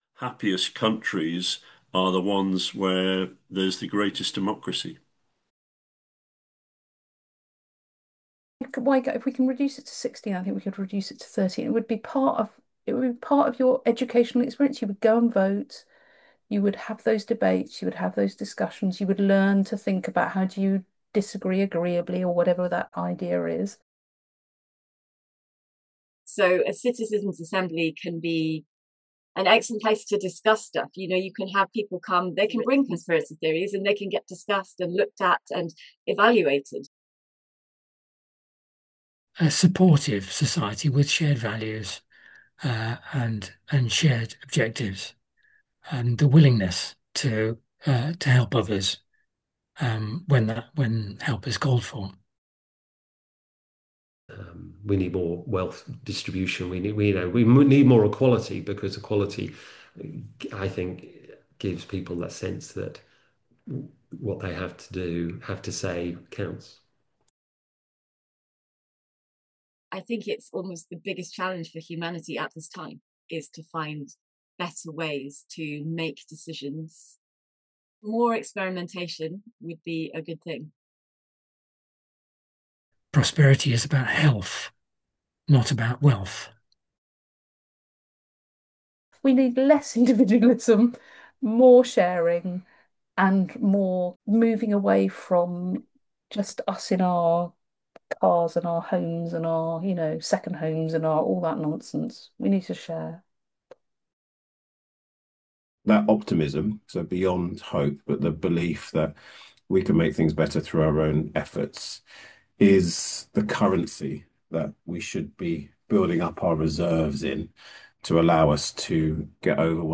Exhibition
a soundscape of recorded conversations
democracy-futures-sound-scape.m4a